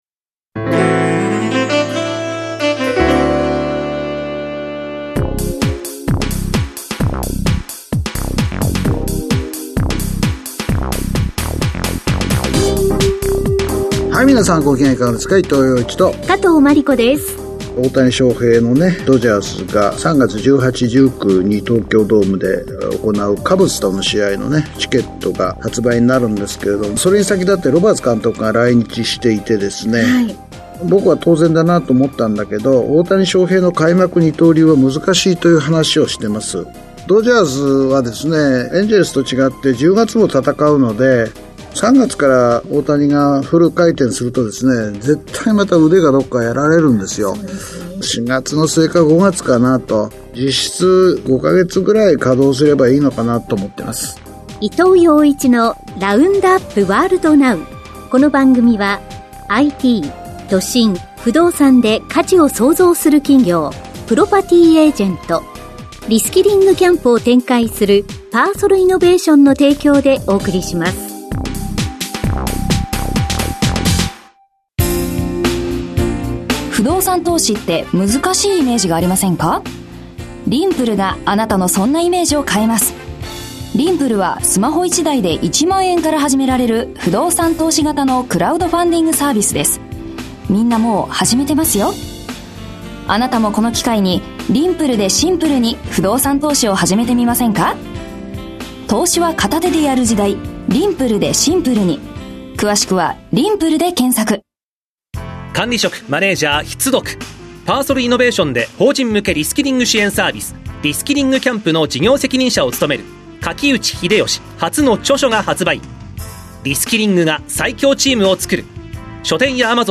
… continue reading 460 episod # ニューストーク # ニュース # ビジネスニュース # NIKKEI RADIO BROADCASTING CORPORATION